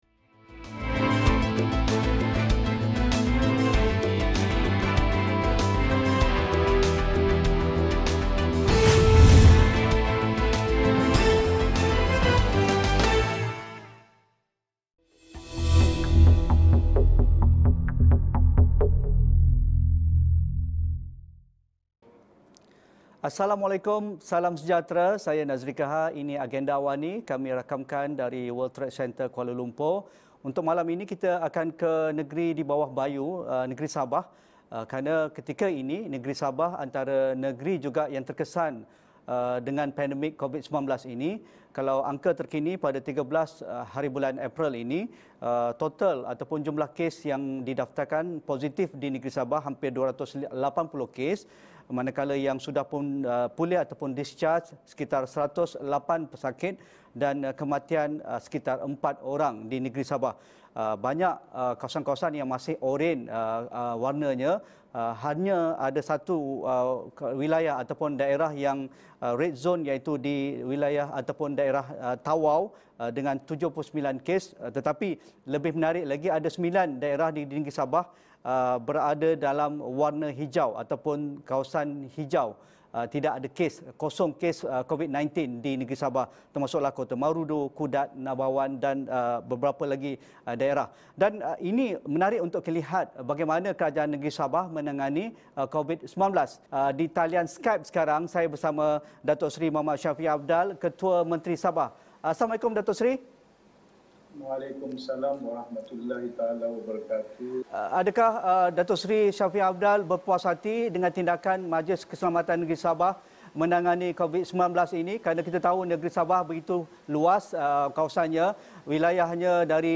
Bagaimana negeri Sabah menangani krisis COVID-19? Temu bual